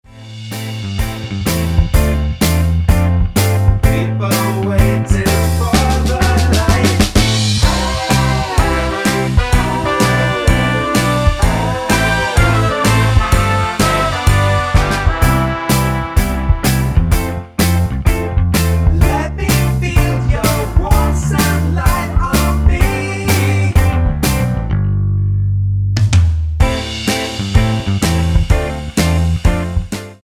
--> MP3 Demo abspielen...
Tonart:C mit Chor